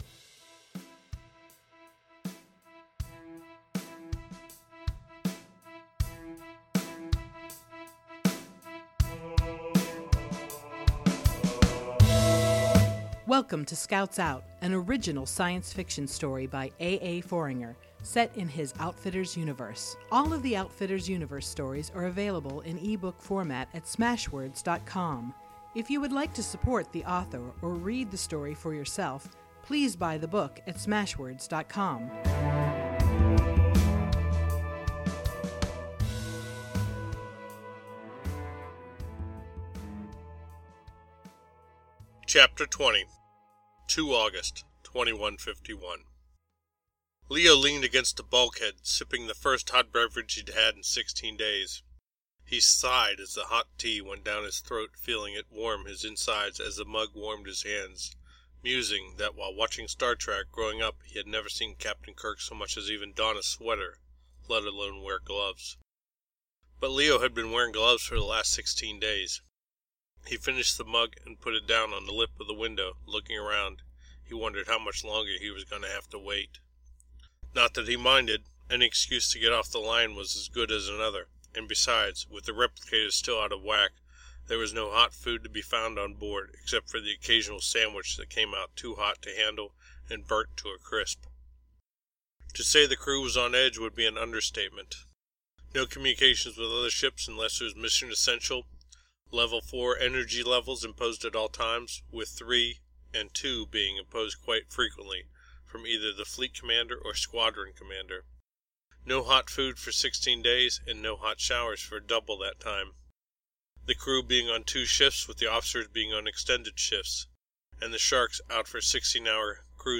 science fiction podcast